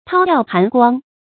韜曜含光 注音： ㄊㄠ ㄧㄠˋ ㄏㄢˊ ㄍㄨㄤ 讀音讀法： 意思解釋： 掩藏隱匿光明。